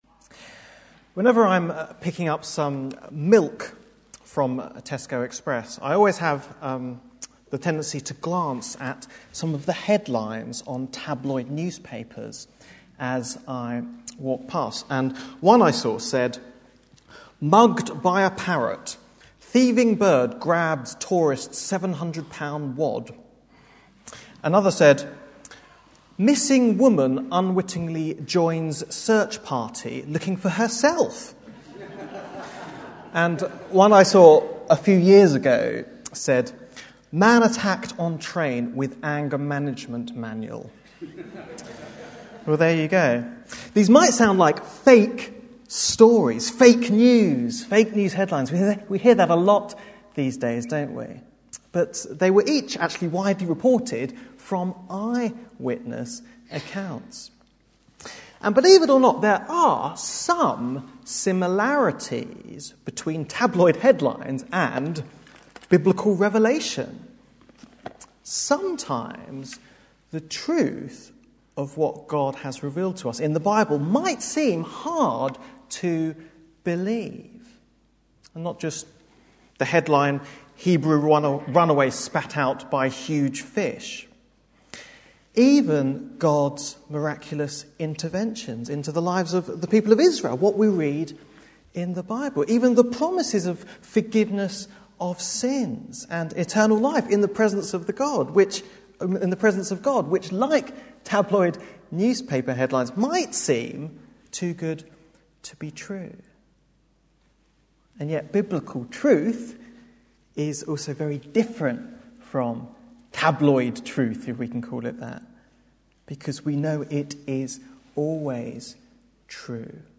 Bible Text: 2 Peter 1:12-21 | Preacher